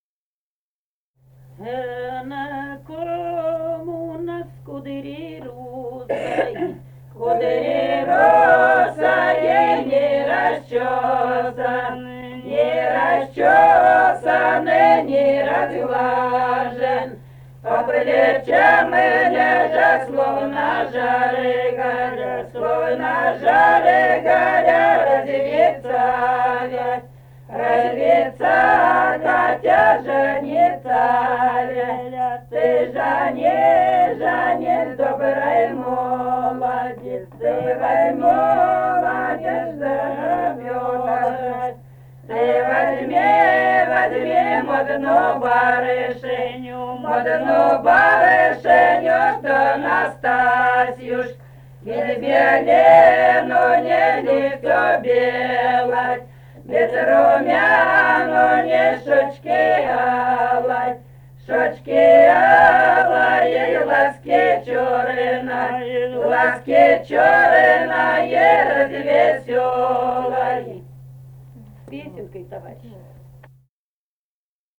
Этномузыкологические исследования и полевые материалы
(свадебная)
Самарская область, с. Усманка Борского района, 1972 г. И1317-08